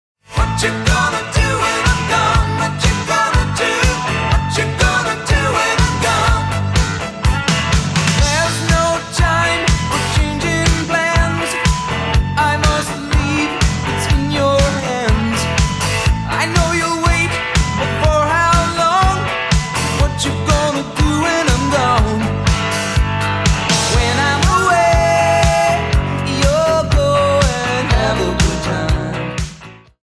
guitar, drums, keyboards, vocals
bass